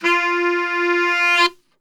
F 2 SAXSWL.wav